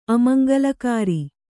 ♪ amaŋgalakāri